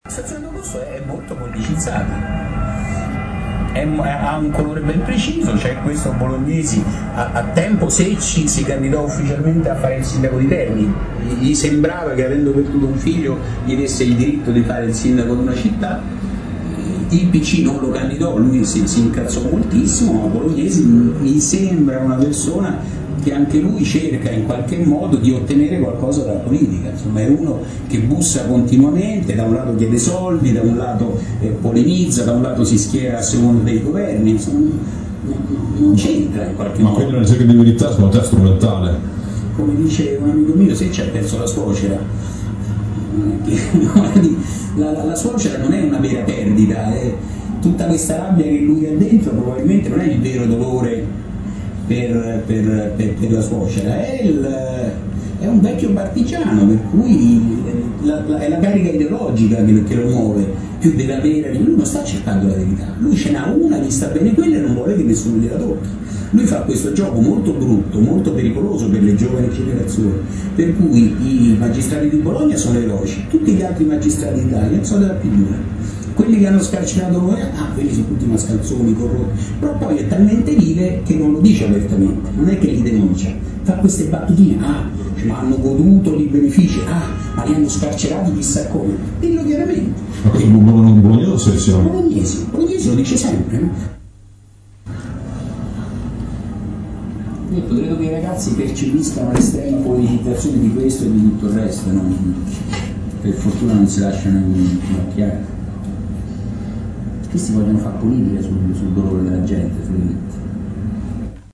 L’ex terrorista dei Nar aveva detto nei giorni scorsi che l’intervista era stata registrata senza il suo consenso.